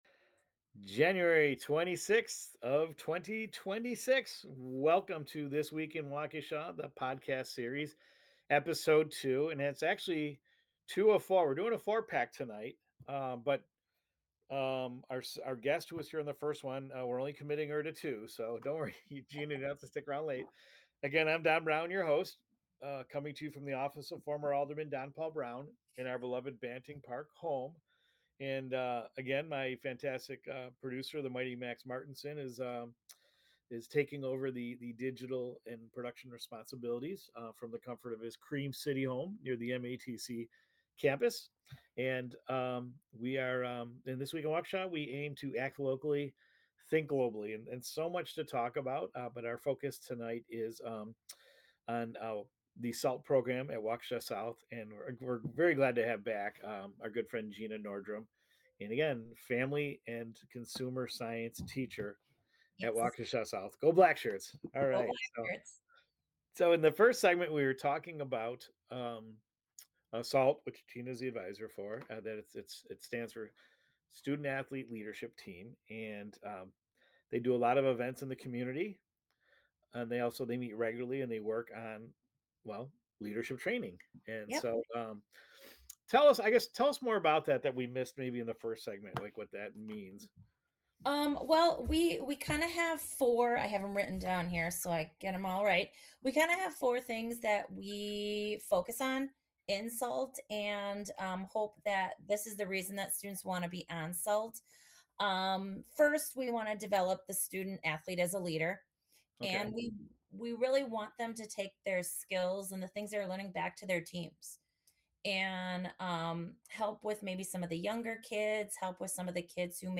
The conversation also touches on mentoring younger students, summer service projects, and why empowering young leaders—especially at the high school level—matters now more than ever.